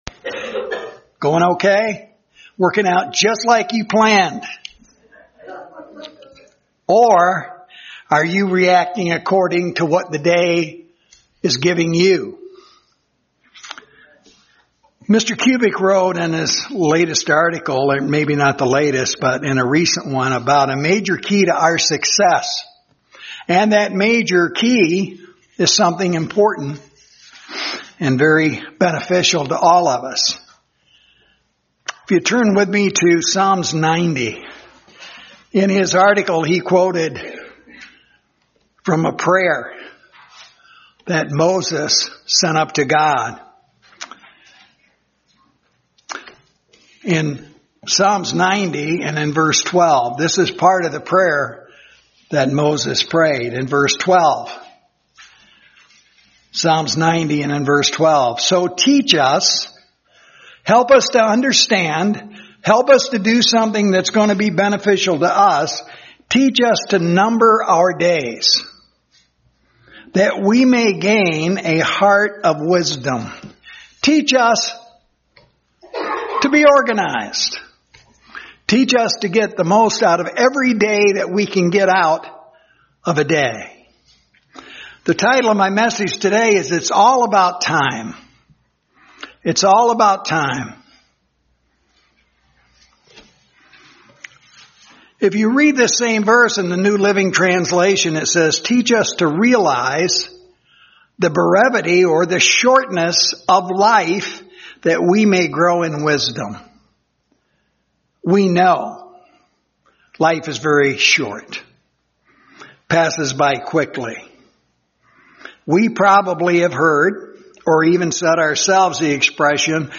Given in Grand Rapids, MI